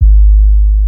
puredata/resources/808_drum_kit/classic 808/MATIC 808.wav at b3ea18f56dbec4bcb9fef0fba25d29bfa2cf5e9e
MATIC 808.wav